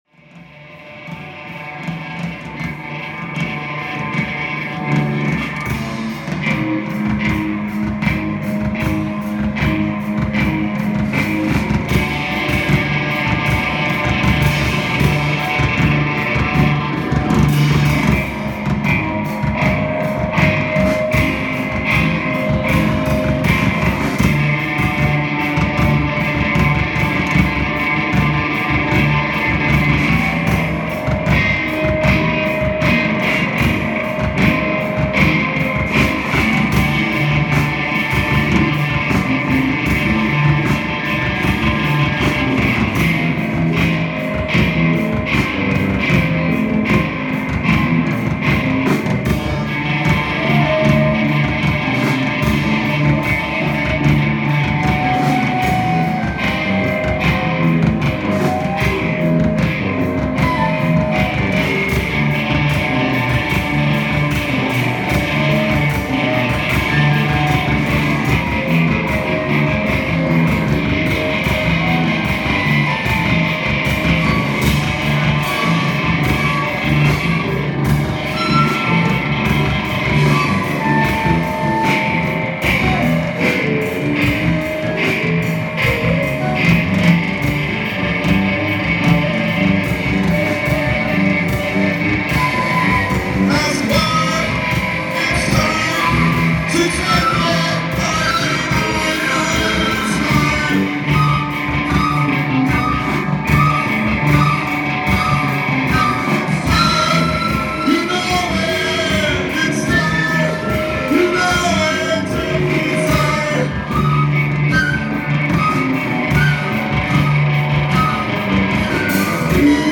ALL MUSIC IS IMPROVISED ON SITE
bass/voice
tenor sax/voice
drums